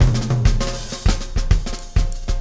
Extra Terrestrial Beat 05.wav